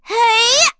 voices / heroes / en
Erze-Vox_Attack4.wav